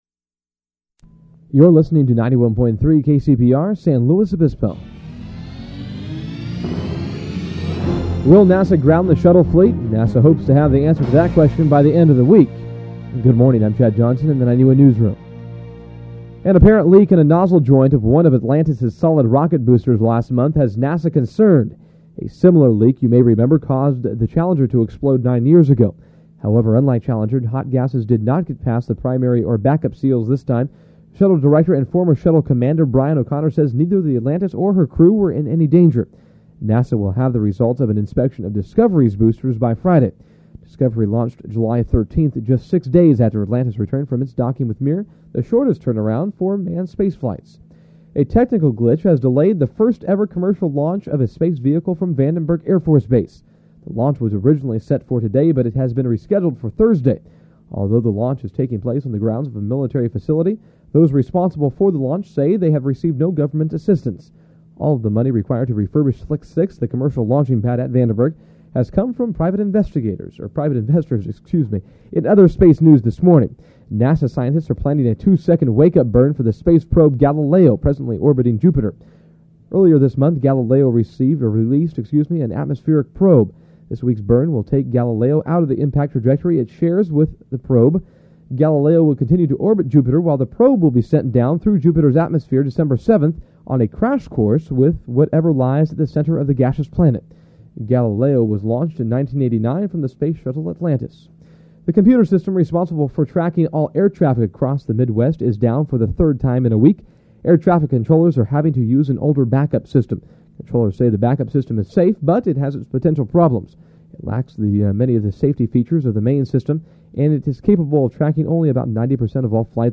9 AM News
Form of original Audiocassette